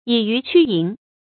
以魚驅蠅 注音： ㄧˇ ㄧㄩˊ ㄑㄩ ㄧㄥˊ 讀音讀法： 意思解釋： 見「以魚驅蠅」。